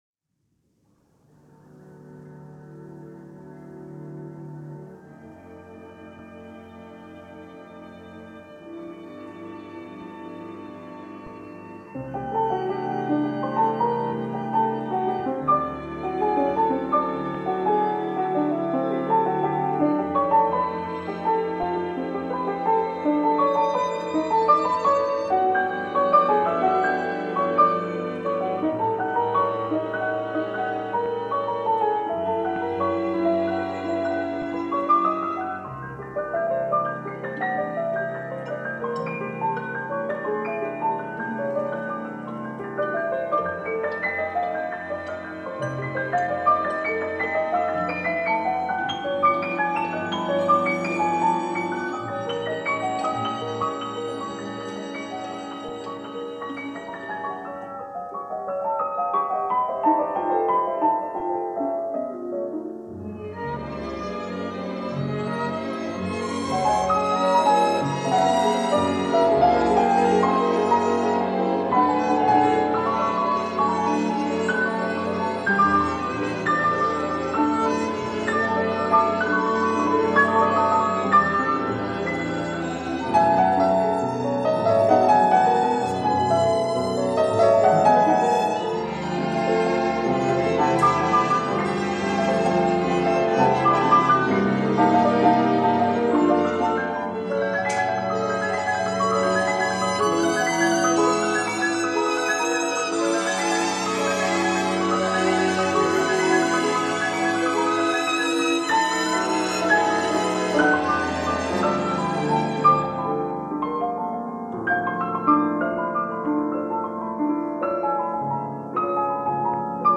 radio Studio performance from 1962
piano.
for piano and orchestra